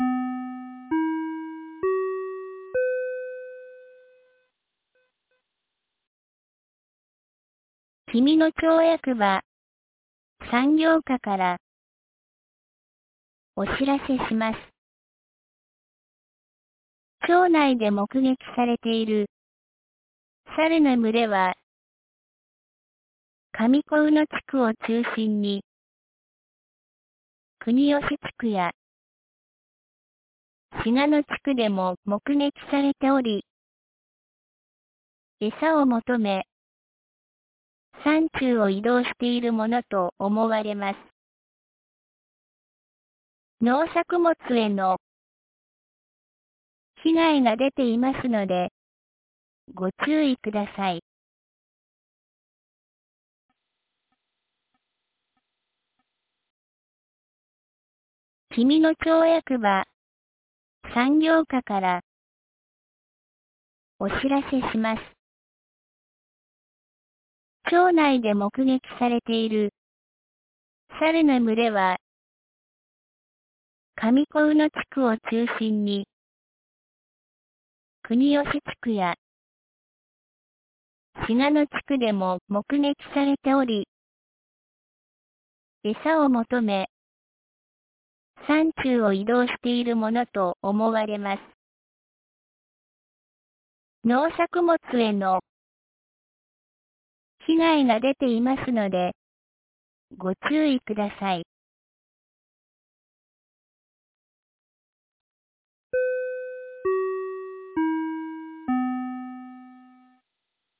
2025年09月16日 12時36分に、紀美野町より上神野地区、下神野地区、国吉地区、志賀野地区、真国地区へ放送がありました。